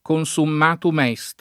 vai all'elenco alfabetico delle voci ingrandisci il carattere 100% rimpicciolisci il carattere stampa invia tramite posta elettronica codividi su Facebook consummatum est [lat. kon S umm # tum $S t ] frase («è finita»)